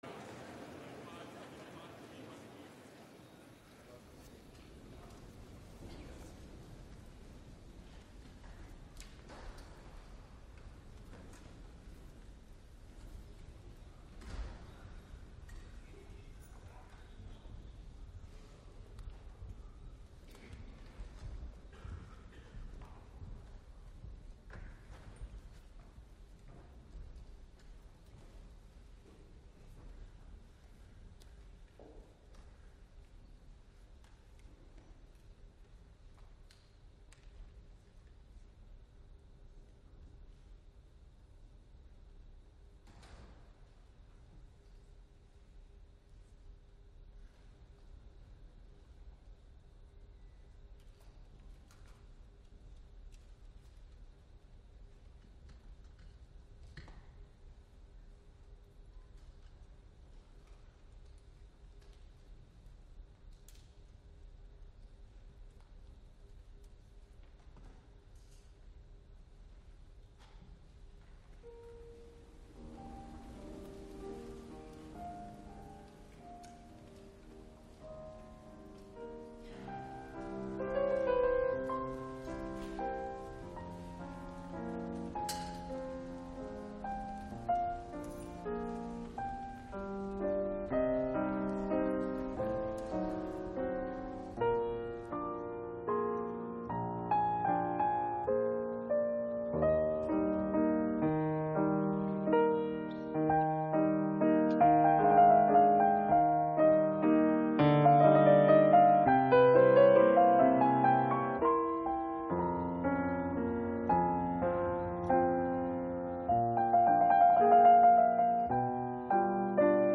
Staatsakt im Plenarsaal des Reichstagsgebäudes